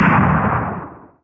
lcannon-turret-fire.wav